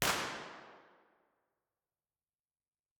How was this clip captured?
impulseresponseheslingtonchurch-002.wav